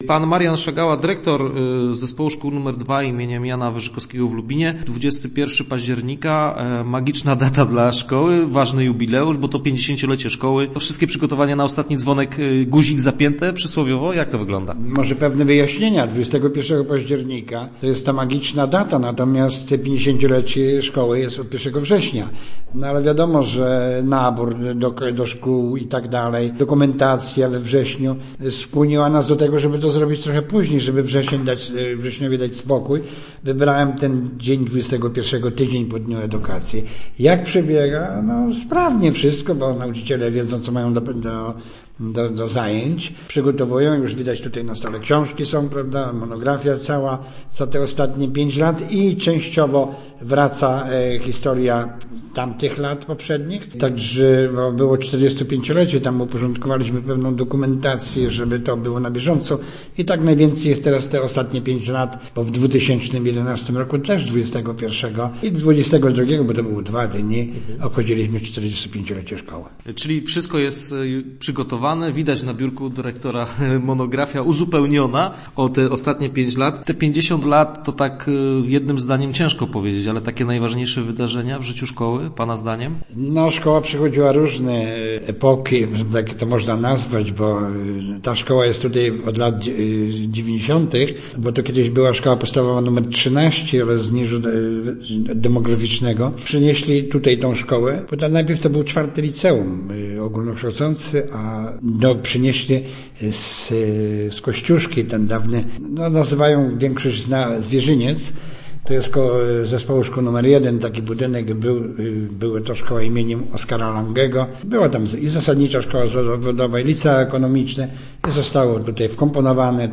Start arrow Rozmowy Elki arrow Szkolny jubileusz